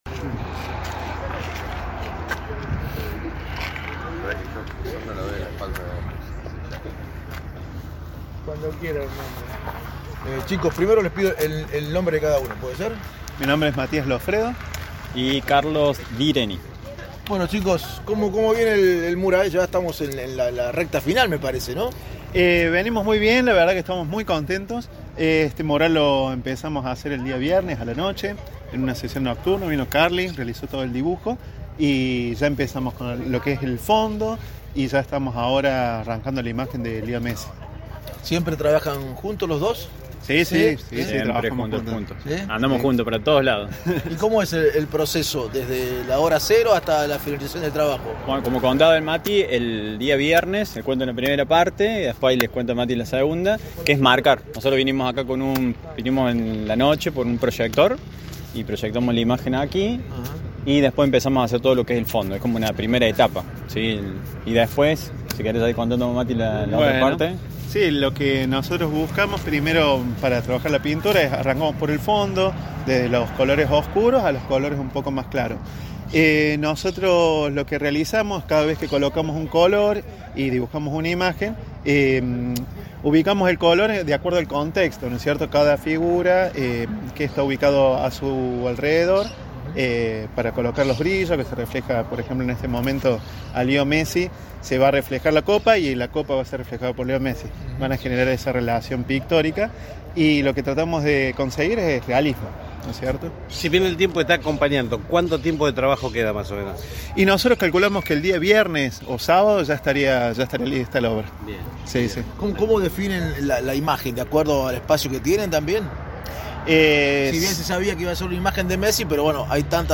Nota con Artistas que están llevando a cabo dicha Obra